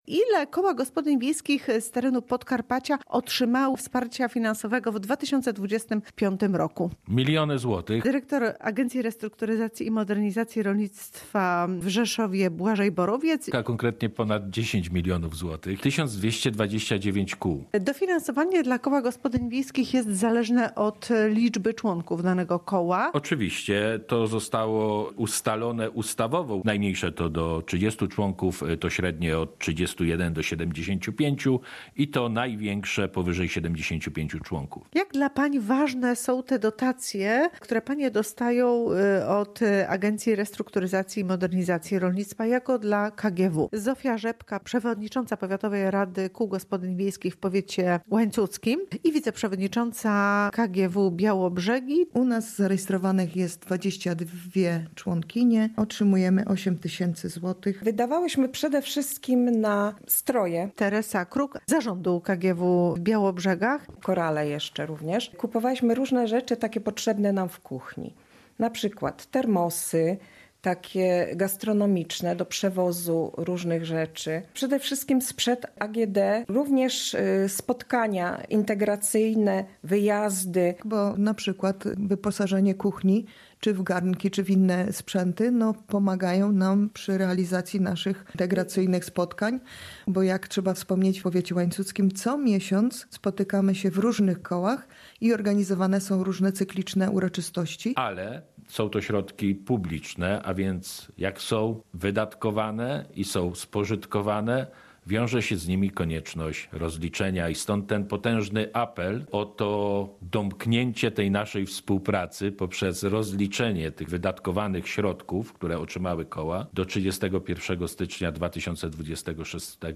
ARiMR przypomina kołom gospodyń wiejskich o obowiązku rozliczenia wsparcia • Relacje reporterskie • Polskie Radio Rzeszów